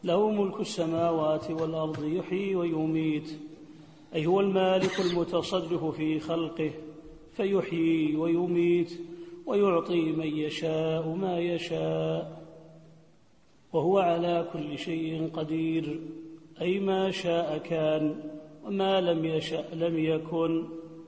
التفسير الصوتي [الحديد / 2]